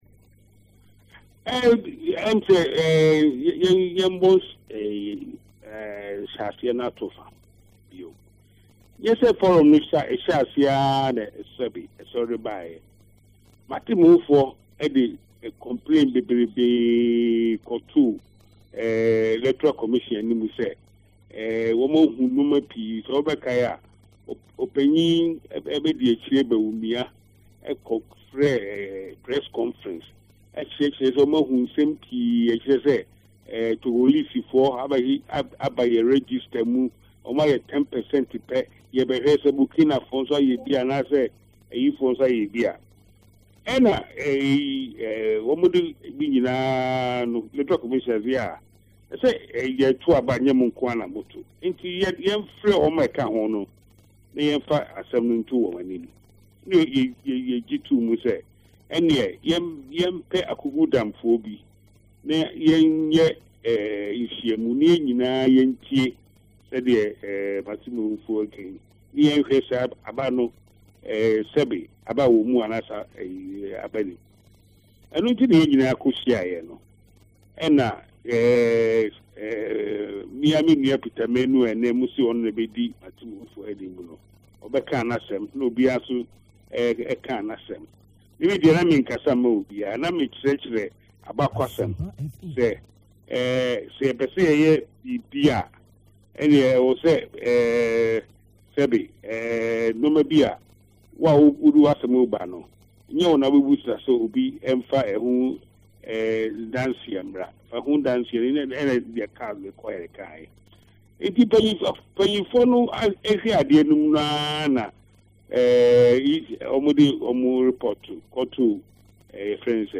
He told Asempa FM that the NPP can choose to opt out of the 2016 elections because the request for a new register will never materialize as the EC is serious with its business.